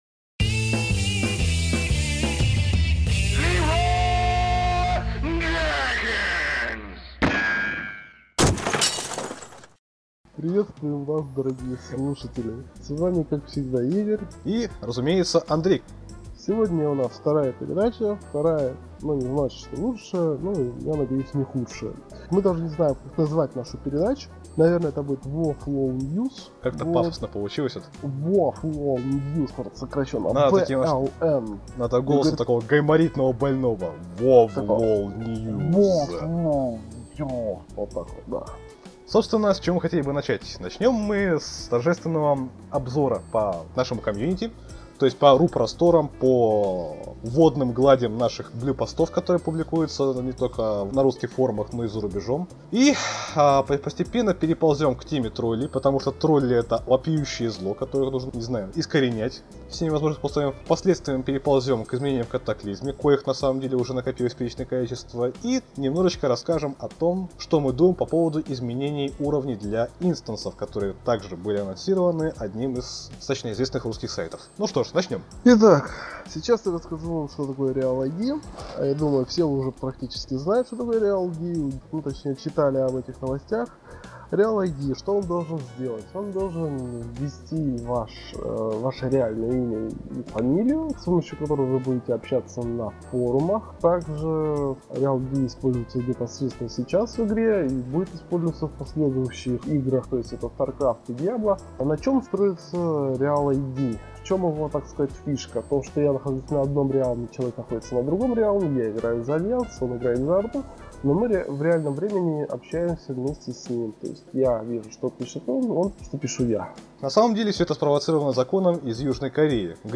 Исправим этот дефект в следующий раз.